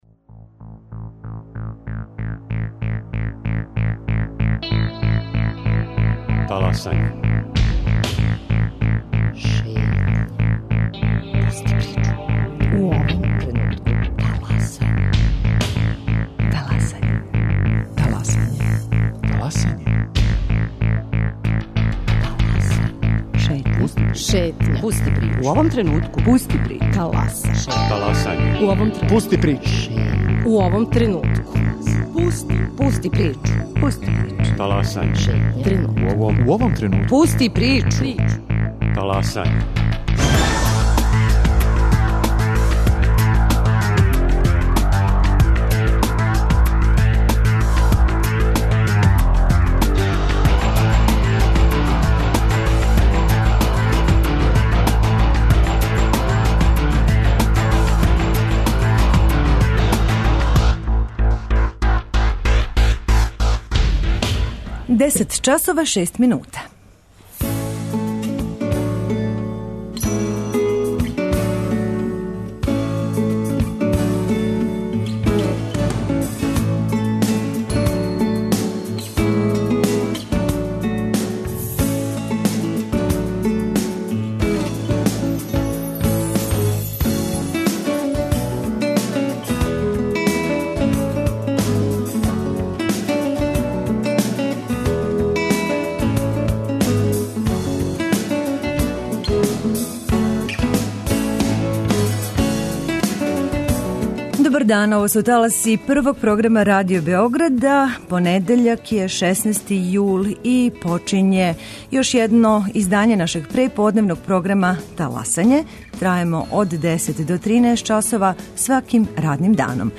на директној телефонској вези